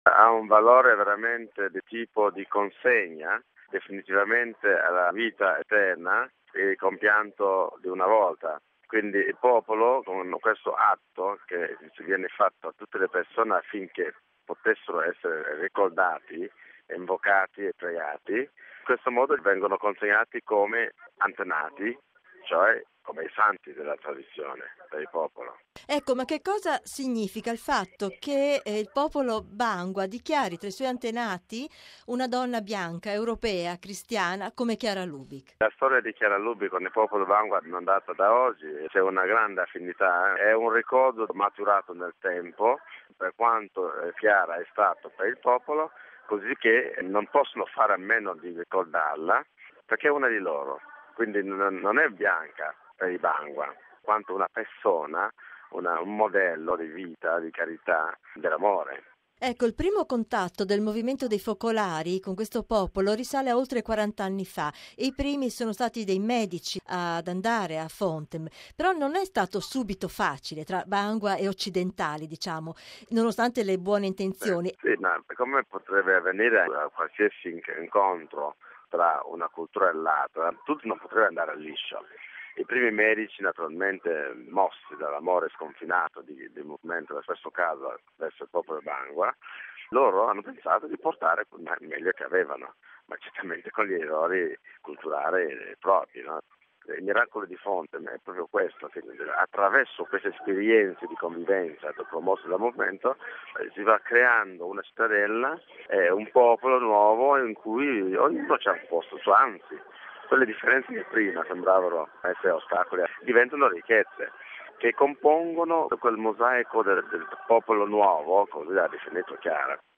Ma che valore ha la celebrazione del “Cry day”? Al microfono di